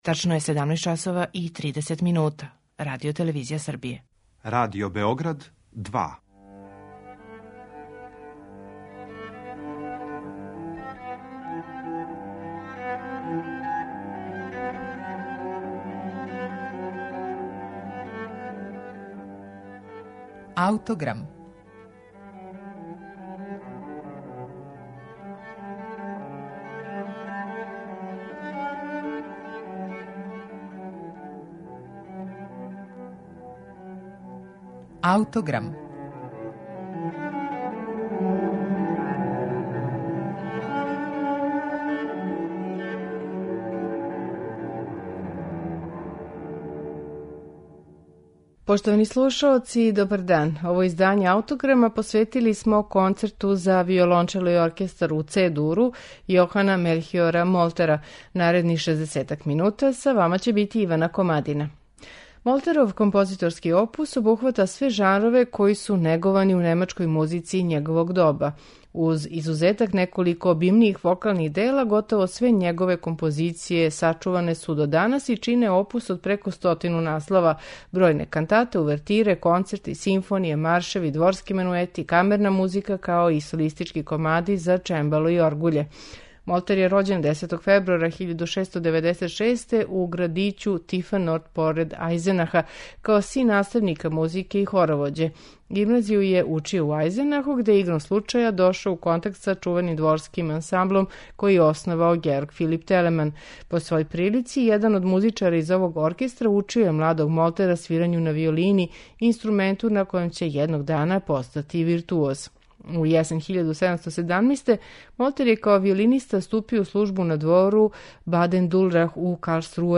Молтеров Концерт за виолончело и оркестар у Ц-дуру